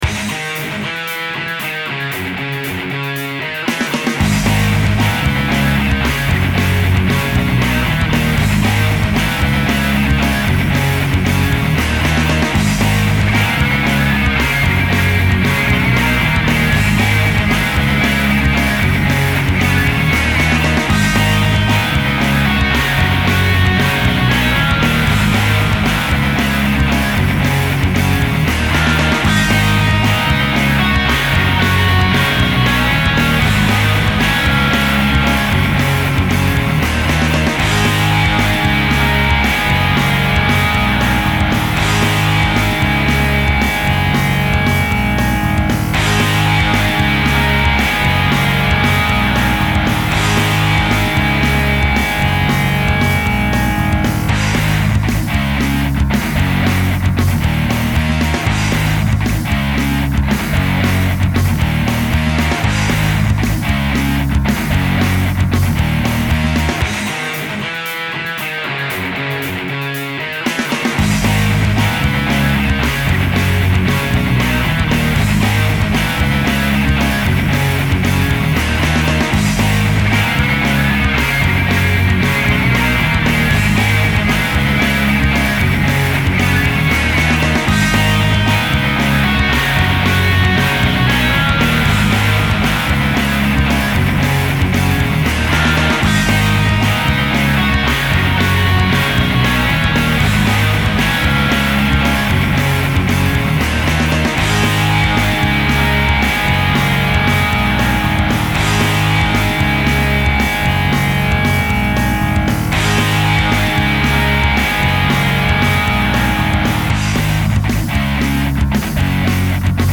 rock
Sonidos: Música